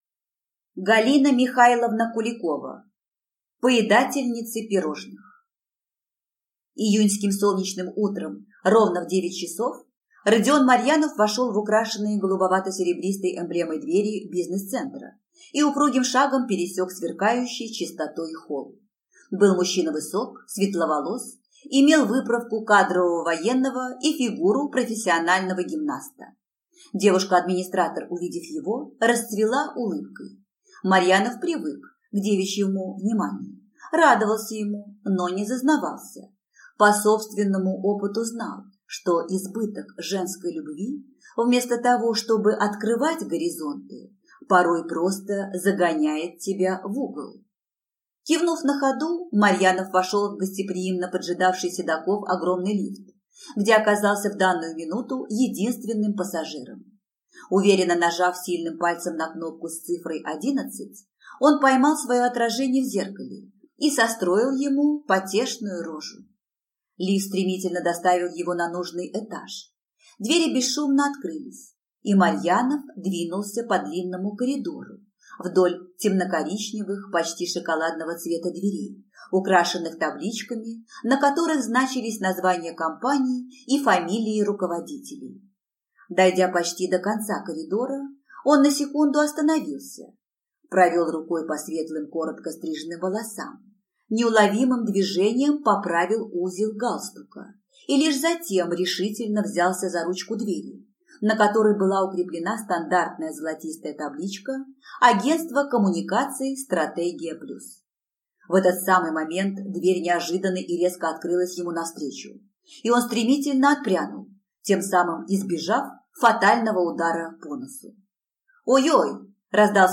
Аудиокнига Поедательницы пирожных | Библиотека аудиокниг